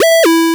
retro_synth_beeps_09.wav